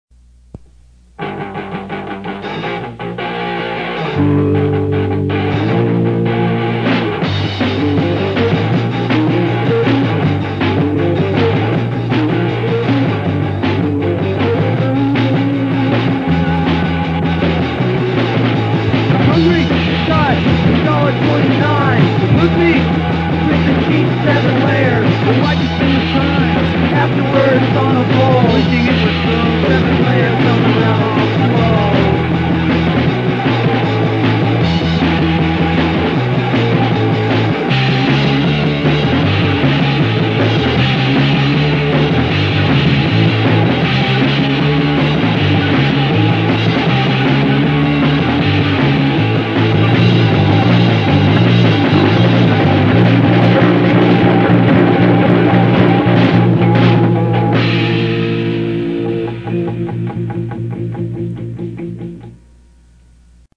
Our 90s fast metal punk band with a softy heart
in the basement recording session, 90s!